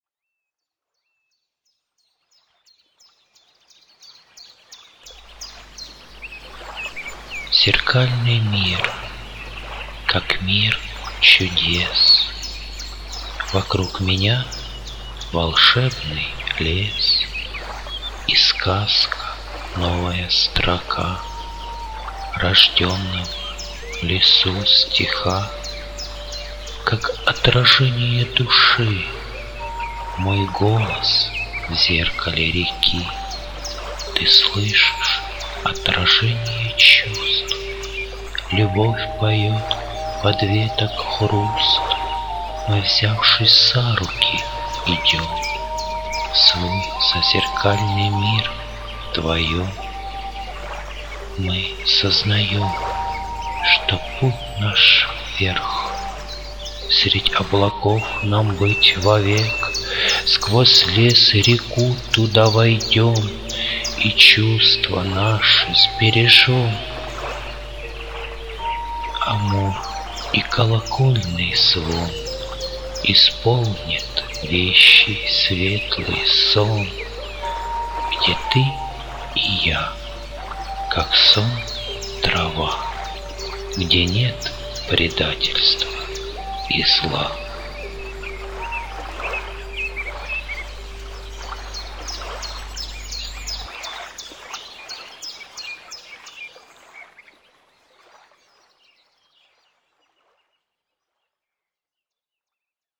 авторский аудио-стих в исполнении автора